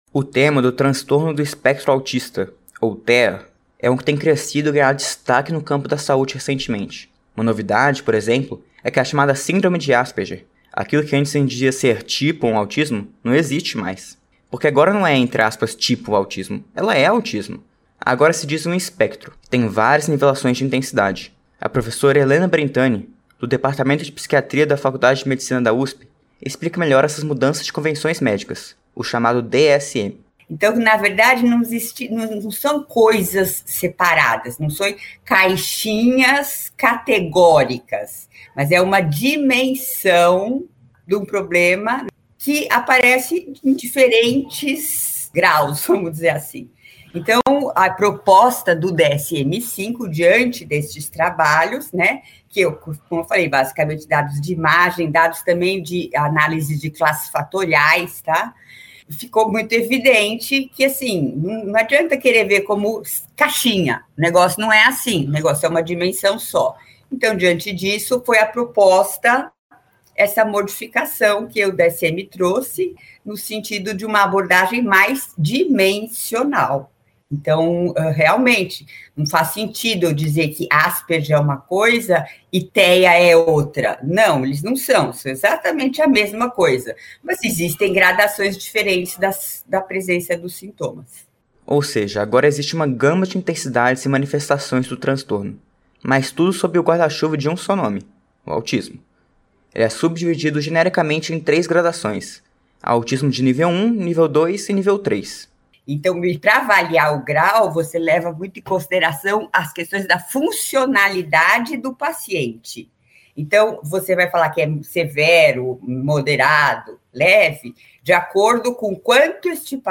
em entrevista a rádio USP.